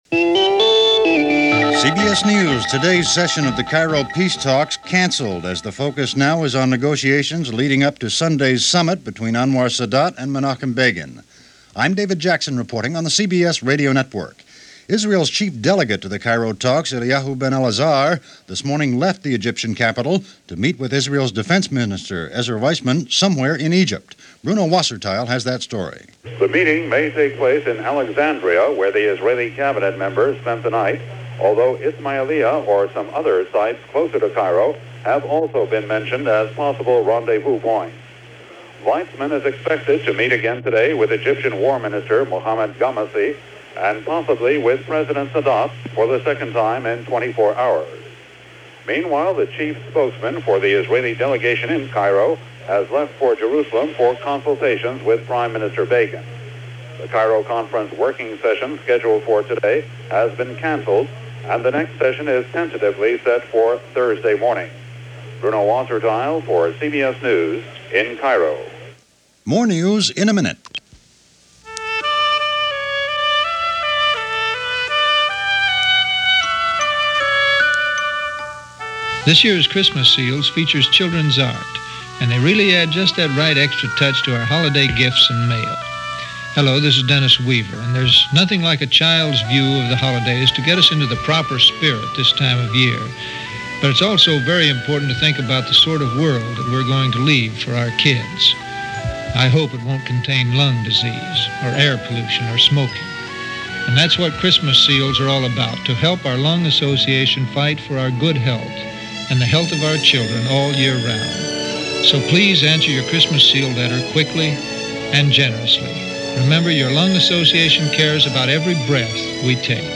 That’s a small slice of what went on, this otherwise mundane December 21st in 1977, as reported by CBS Radio News.